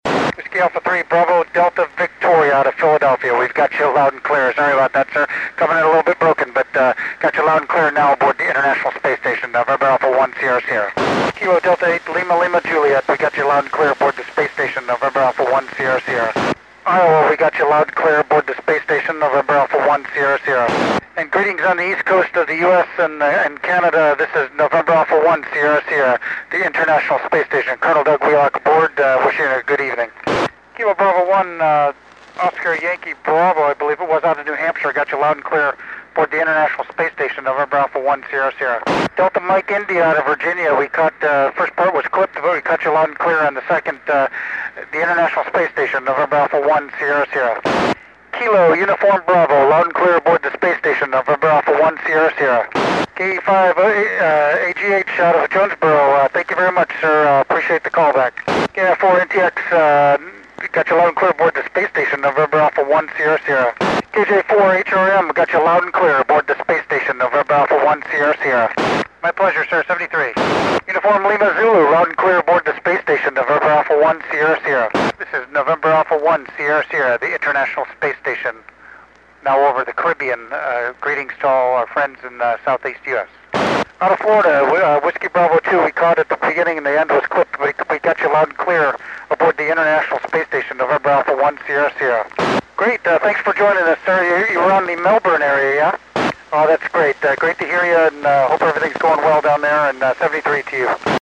When I record, I leave the squelch off, and when I edited the recording, I deleted most of the squelch noise to reduce the size of the recording, so the "squelch tail" separates transmissions by Col. Wheelock.